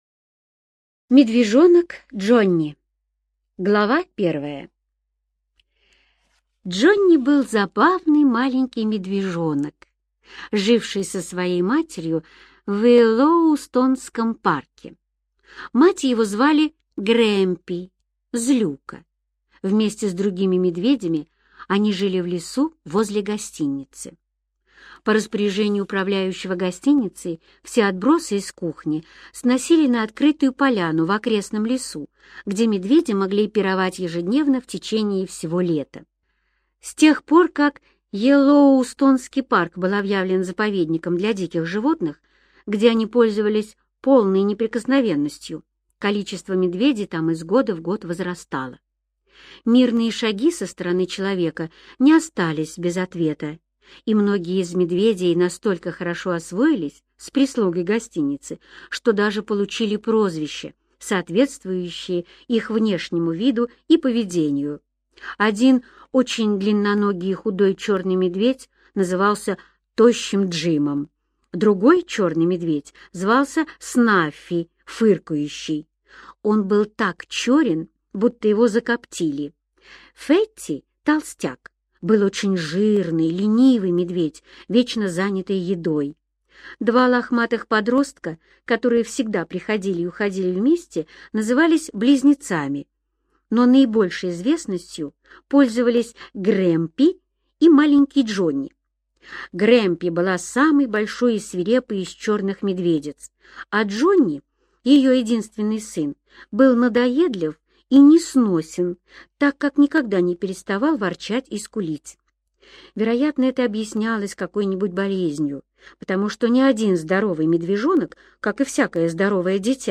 Медвежонок Джонни - аудио рассказ Эрнеста Сетона-Томпсона - слушать онлайн